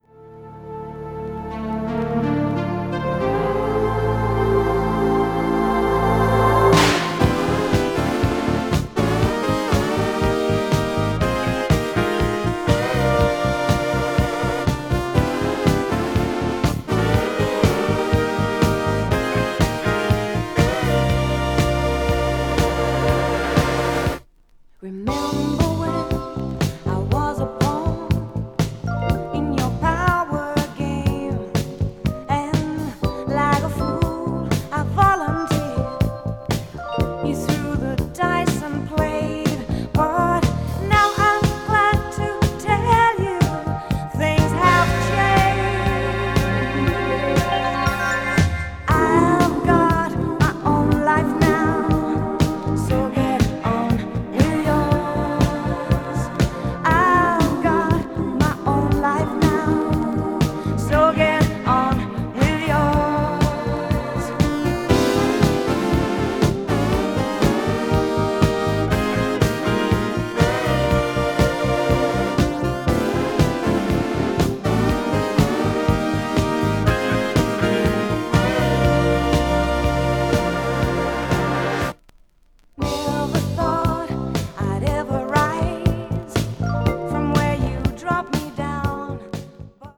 ポップ・ロック度がかなりアップしていてレゲエやブルースなども取り入れていますが